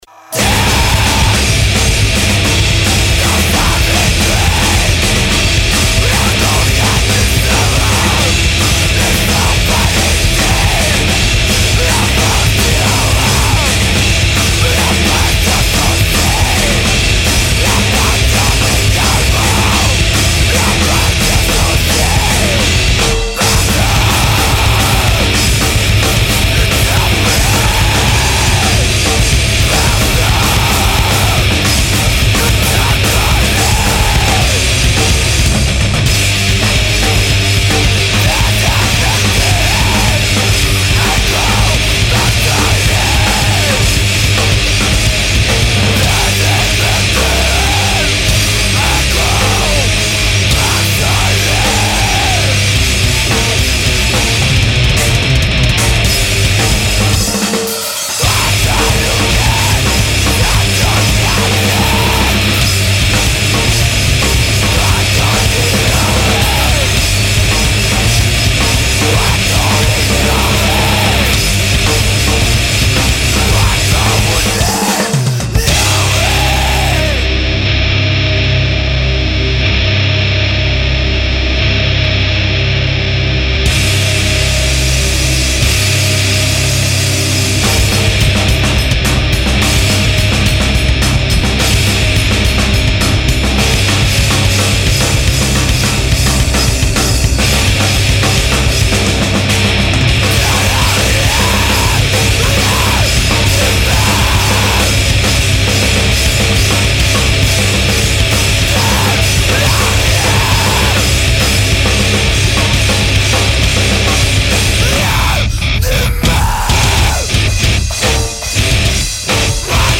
Incredible young power trio from Bulle-Switzerland.
Guitar, drums, screams and no space left for a bass-player !